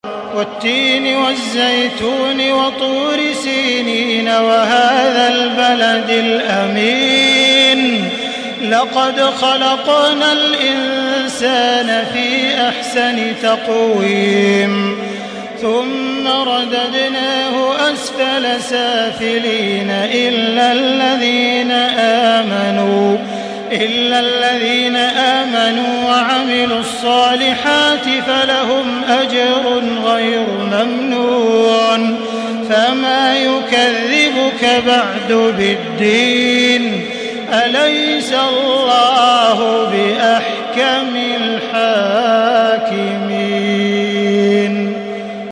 تحميل سورة التين بصوت تراويح الحرم المكي 1435
مرتل